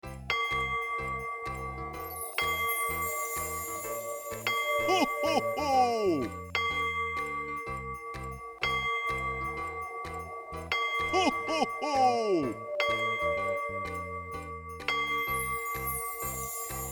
cuckoo-clock-08.wav